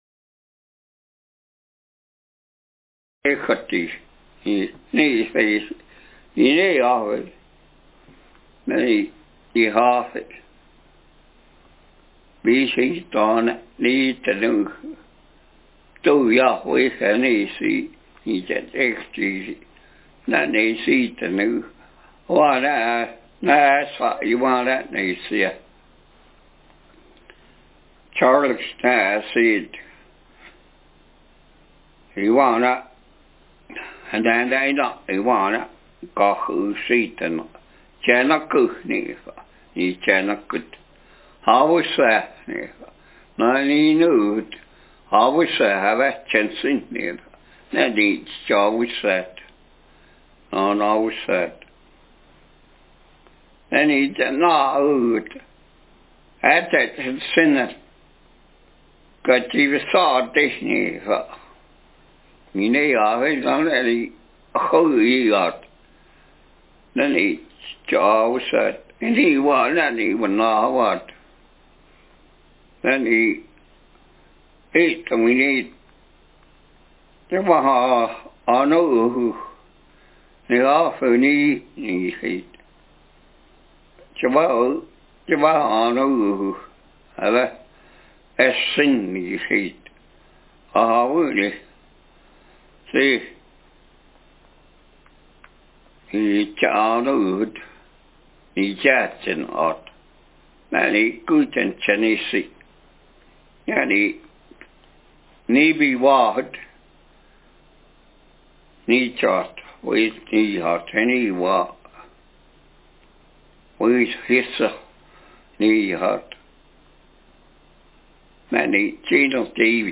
Speaker sex m Text genre personal narrative